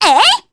Reina-Vox_Attack3_jp.wav